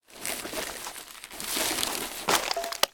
rummage.wav